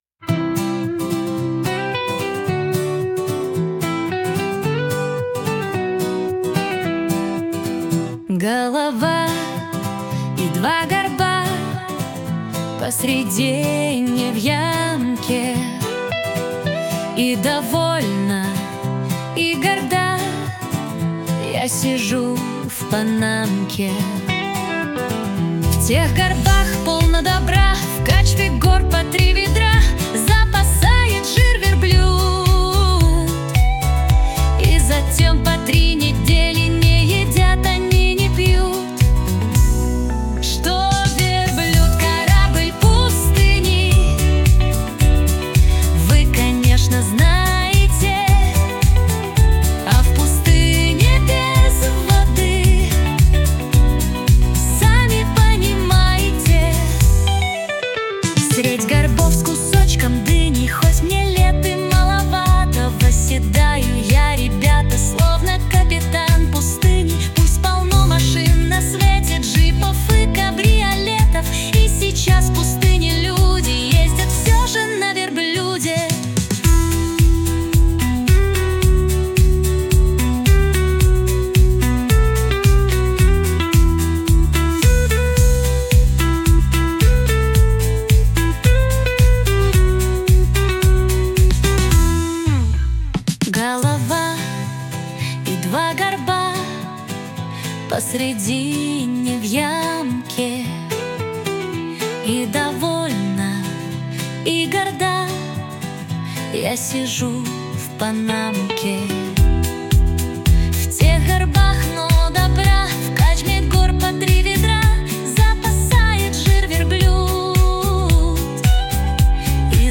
• Аранжировка: Ai
• Жанр: Детская